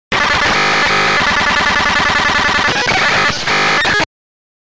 Звуки triggered
Звук, от которого мозг взрывается (эффект триггера)